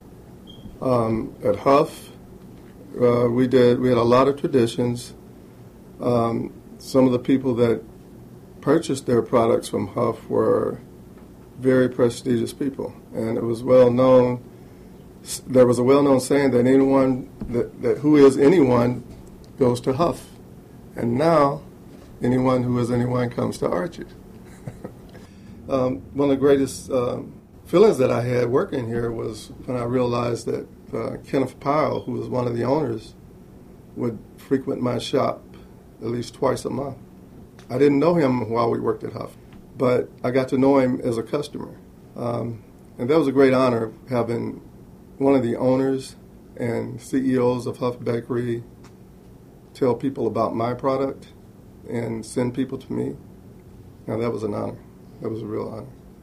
| Source: Cleveland Regional Oral History Collection